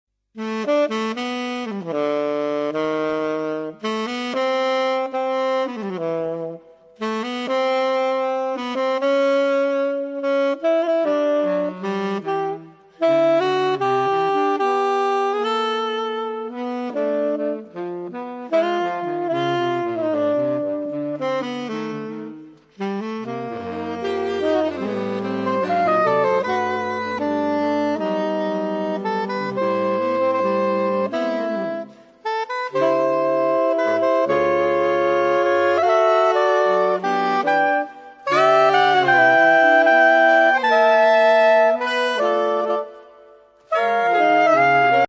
Obsazení: 4 Saxophone (SATBar)
ist eine Jazzballade